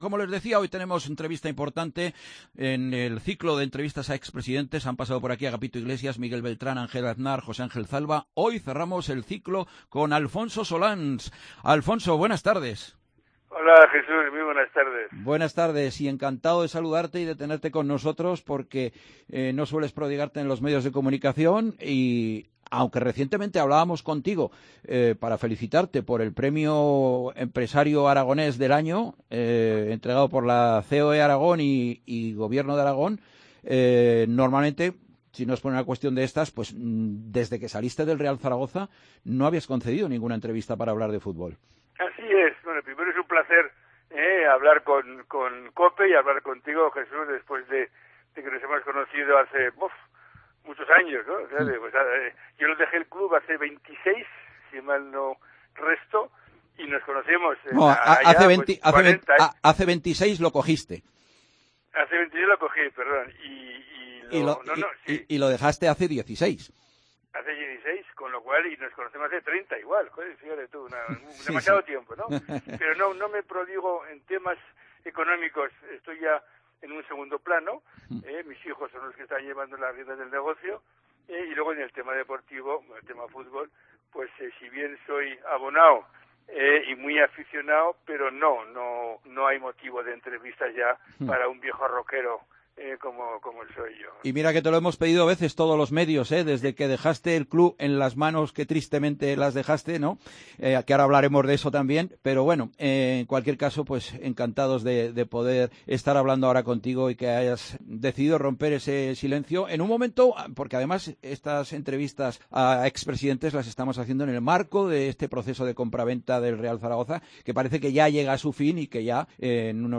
en COPE Zaragoza
Entrevista al ex presidente del Real Zaragoza